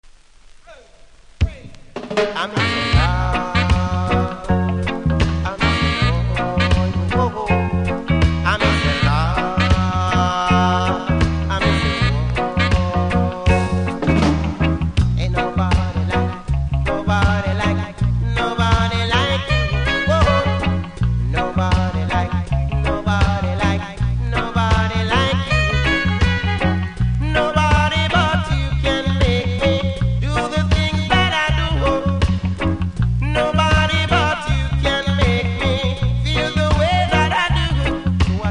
多少うすキズ多少ノイズ感じますがプレイは問題無いレベルなので試聴で確認下さい。